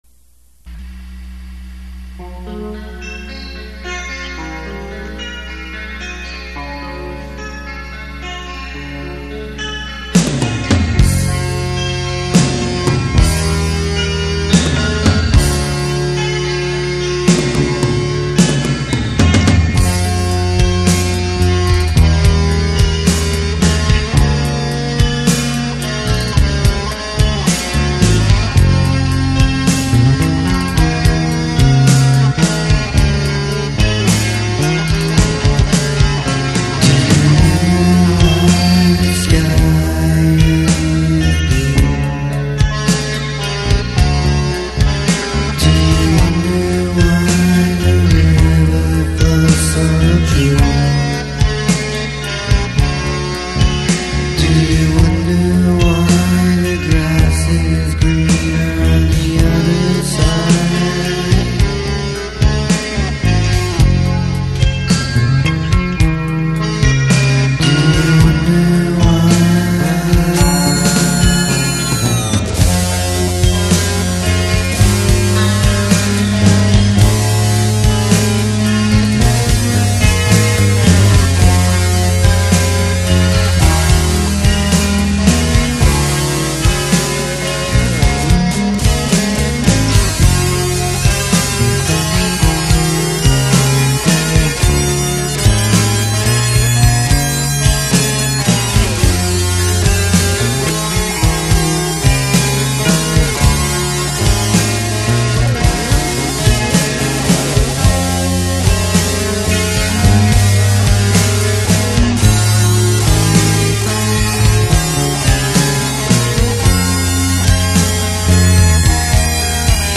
from California on lead guitar
on the background lead guitar, and me on guitar, bass, Alesis SR-16 drum machine, and vocals...
When this song was written, it started out as an acoustic jam, more or less, and evolved into what you hear.